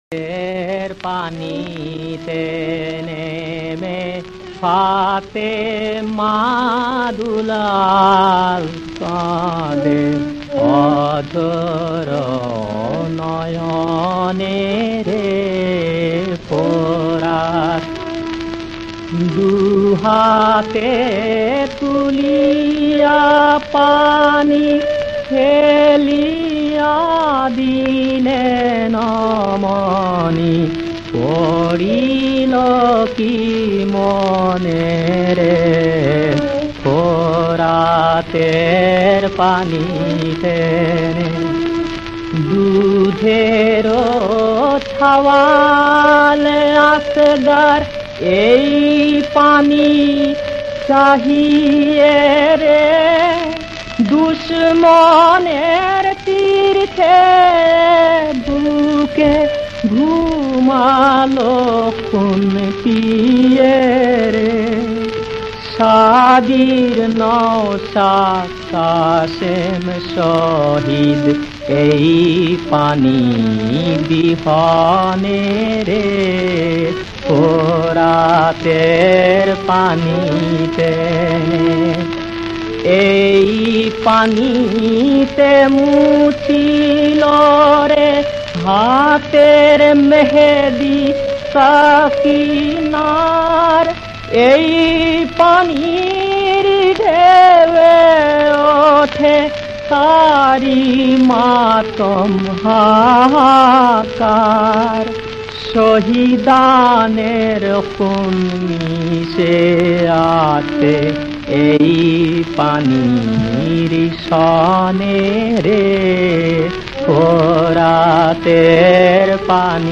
• বিষয়াঙ্গ: ভক্তি (ইসলামী গান, মর্সিয়া)
• সুরাঙ্গ: মর্সিয়া
• তাল: বৈতালিক